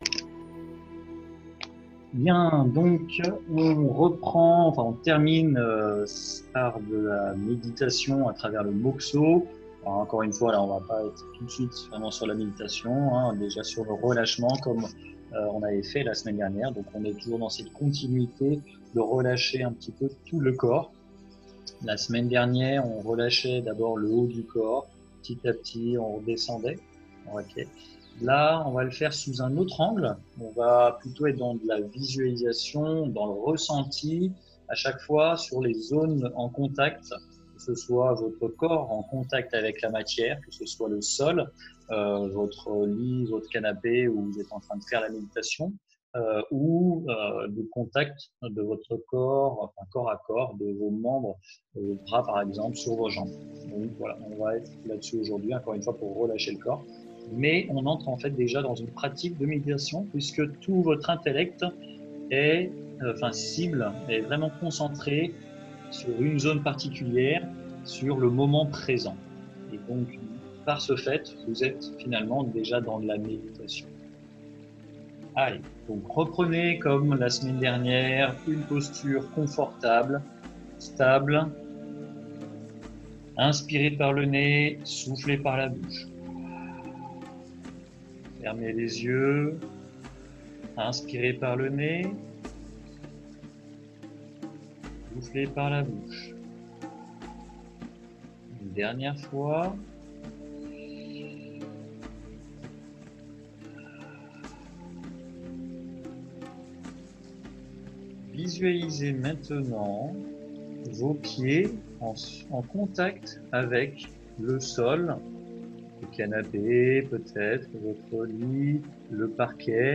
Exemple des clans majeurs : Semaine 5 Semaine 4 Semaine 3 + 5 minutes de Mokuso : Mokuso - Etape 2 : Ressentir - Relâchement en ressentant les zones en contact Semaine 2 Désolé pour l’incident technique qui arrêta la vidéo…
Relâchement-en-ressentant-les-zones-en-contact.mp3